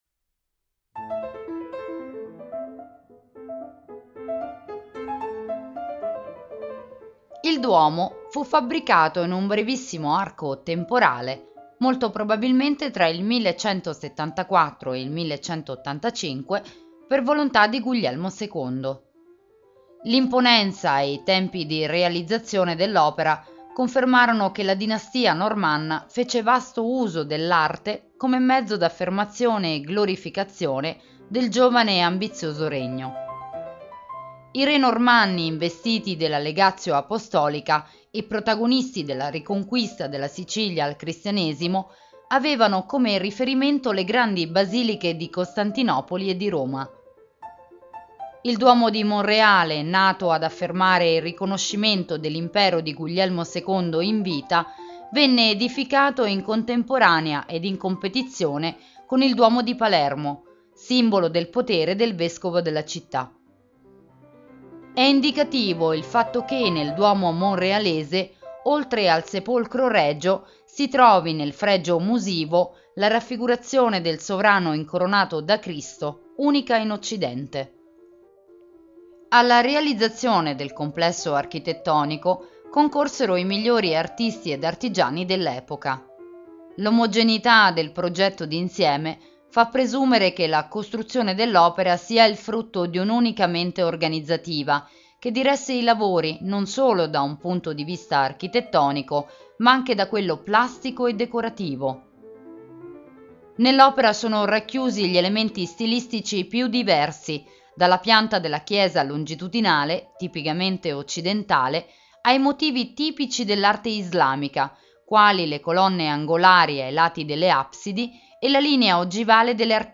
Duomo di Monreale (Audioguida Italiano) - Audiocittà